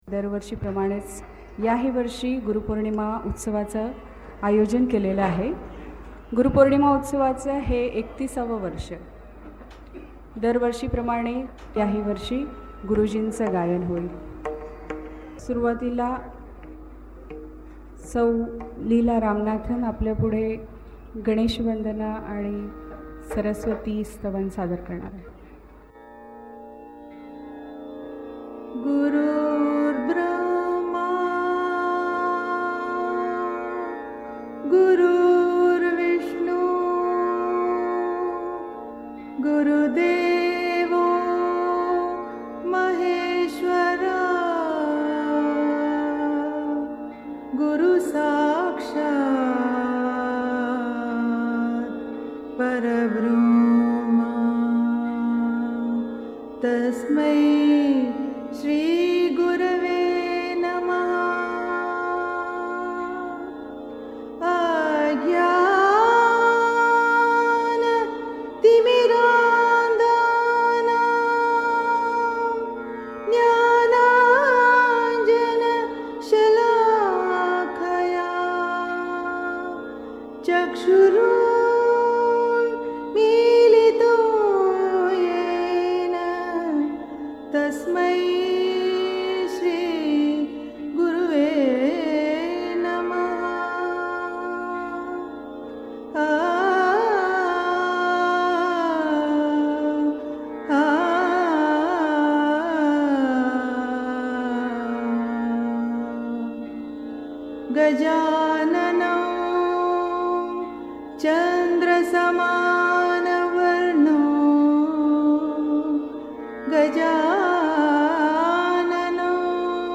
Gurupoornima Utsav.